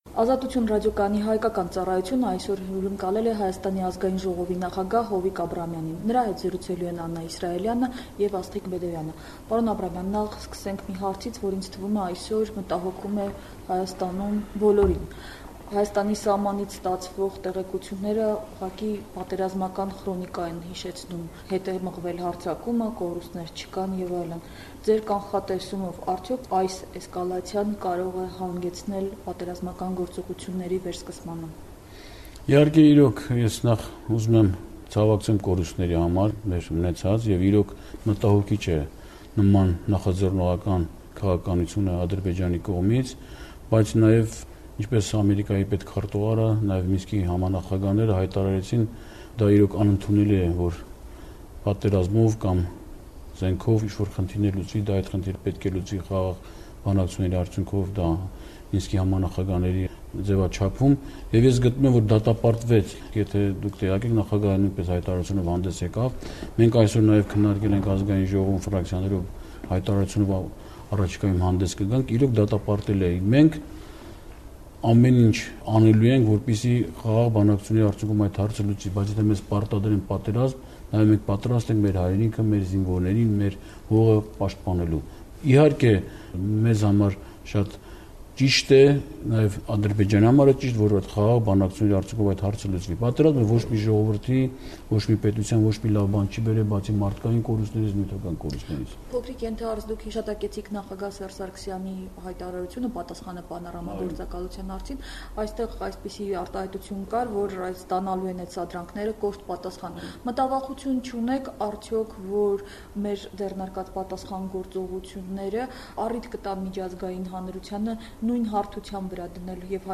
«Ազատություն» ռադիոկայանի «Տեսակետների խաչմերուկ» հաղորդաշարի հյուրը Հայաստանի Ազգային ժողովի նախագահ Հովիկ Աբրահամյանն է: